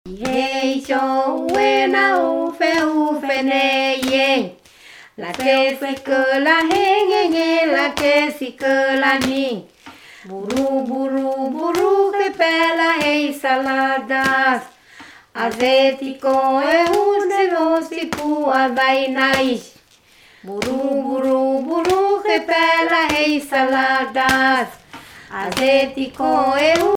Chants enfantins kanaks
Pièce musicale inédite